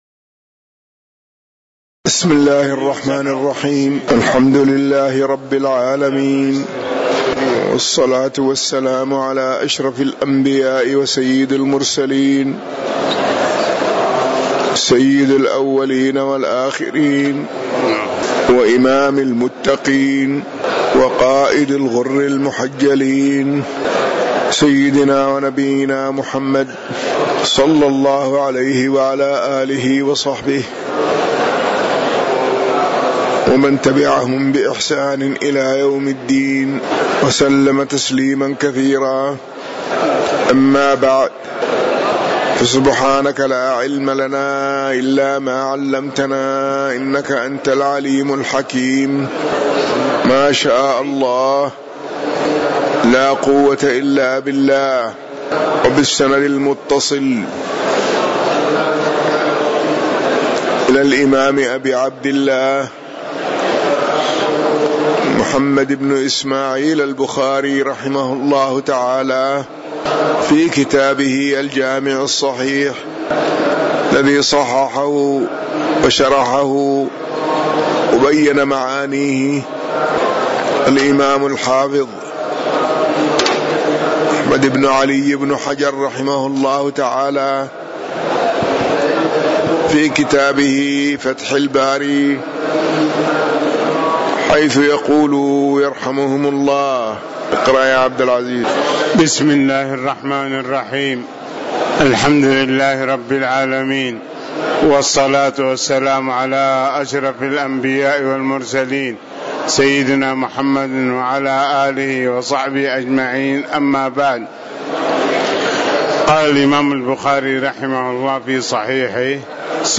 تاريخ النشر ٢٨ شوال ١٤٤٠ هـ المكان: المسجد النبوي الشيخ